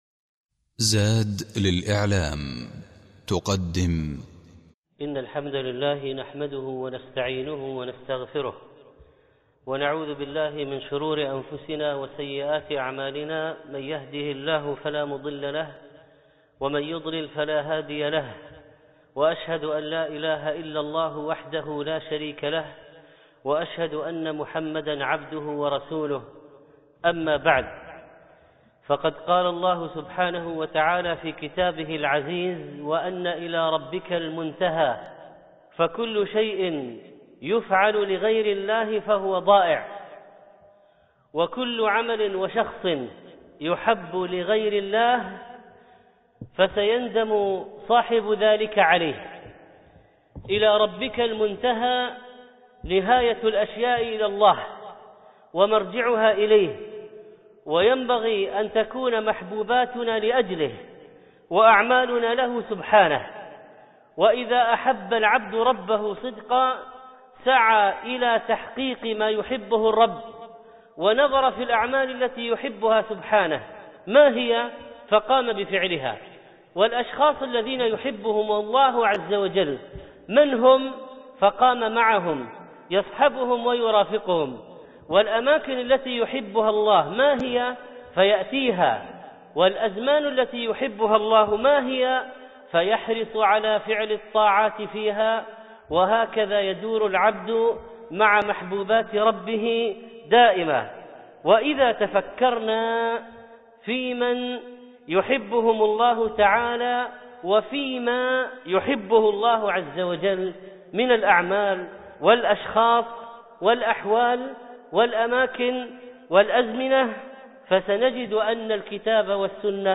الخطبة الأولى